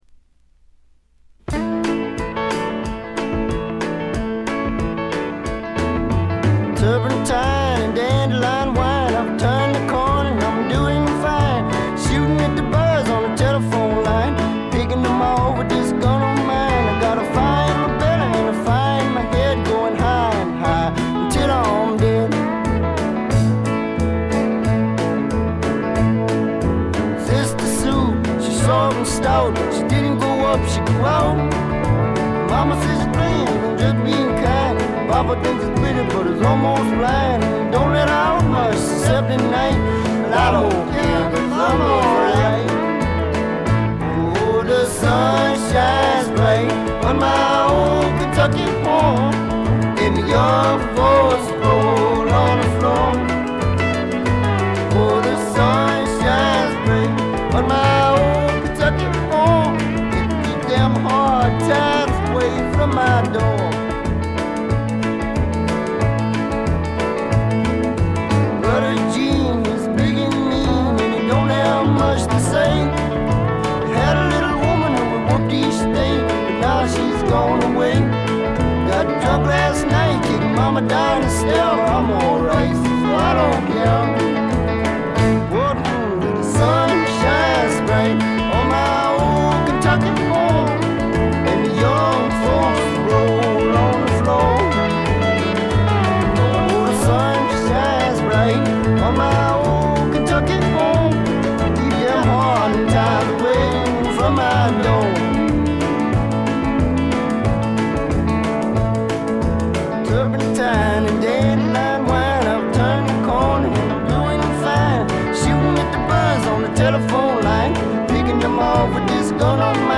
静音部で軽微なバックグラウンドノイズが少し。
試聴曲は現品からの取り込み音源です。
vocals, piano